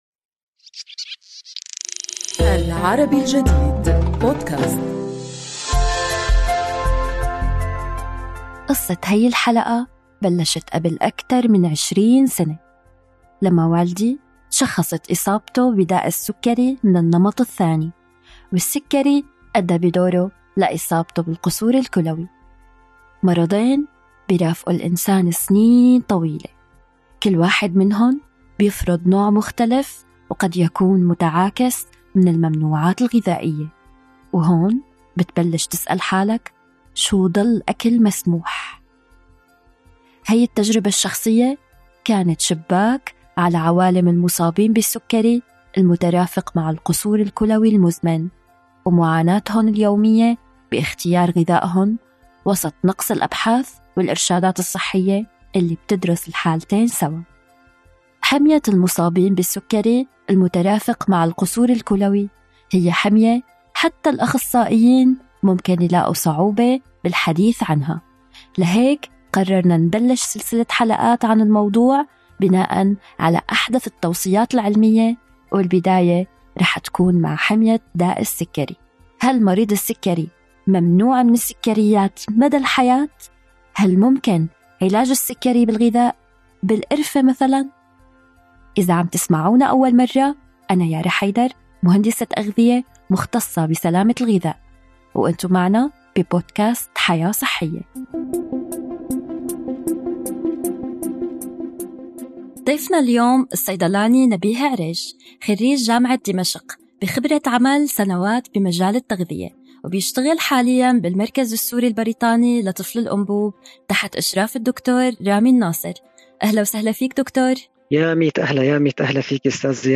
نستضيف الصيدلاني والباحث في مجال التغذية